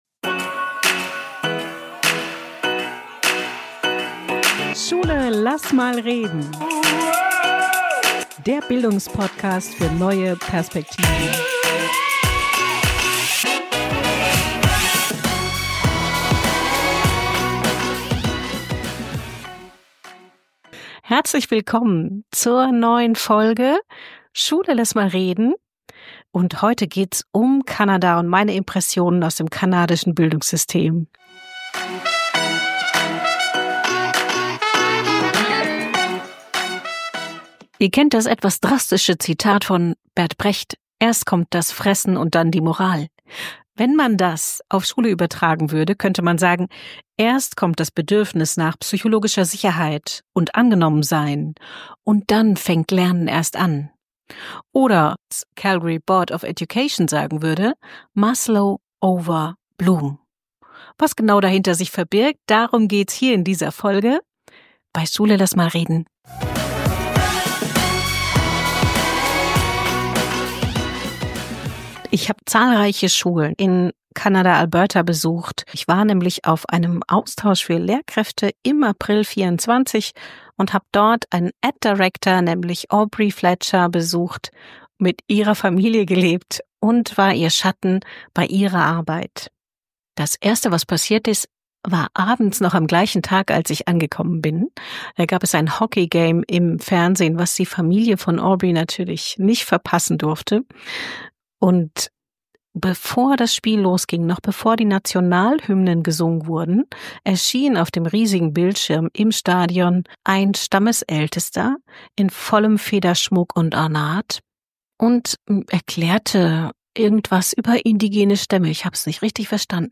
Folge 10: Maslow over Bloom - warum Anerkennung so wichtig ist (nicht nur in Kanada) Interview mit einer kanadischen Kollegin zum Thema "Acknowledgement", Anerkennung und Erfüllung von Grundbedürfnissen